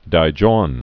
(dī-jôn) also Tae·jon (tī-)